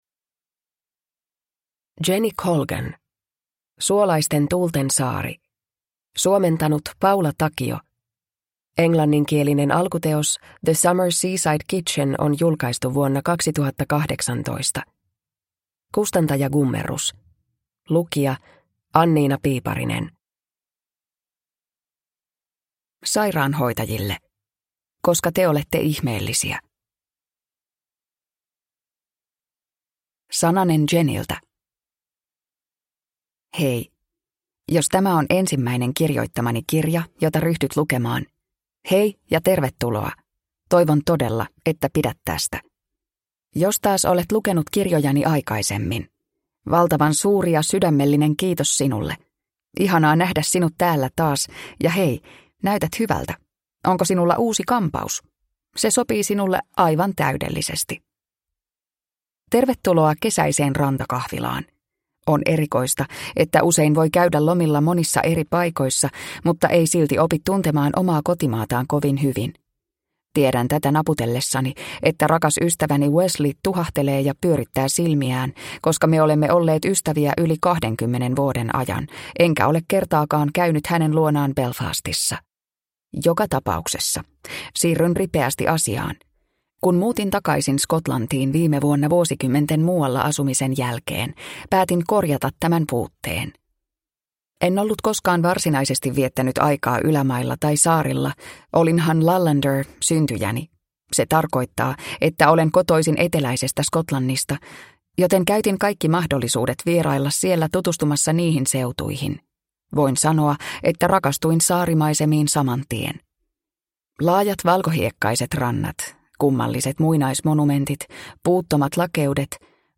Suolaisten tuulten saari – Ljudbok – Laddas ner